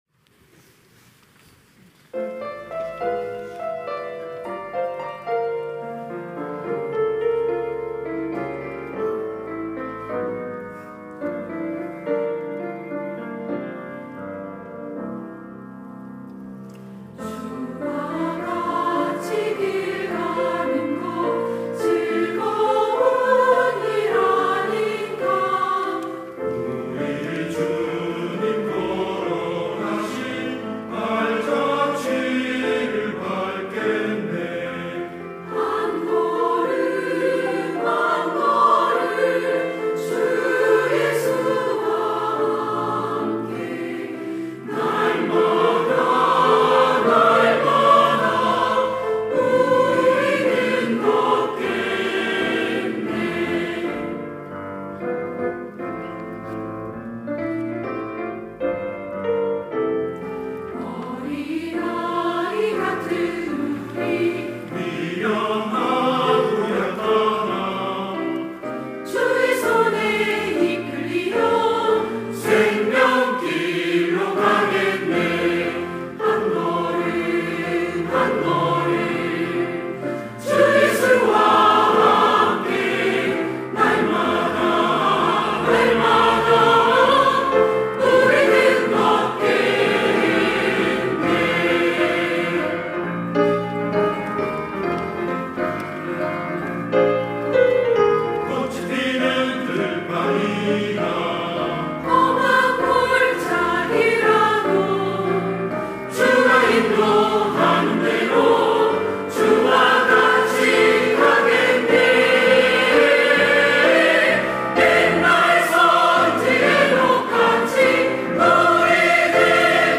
시온(주일1부) - 주와 같이 길 가는 것
찬양대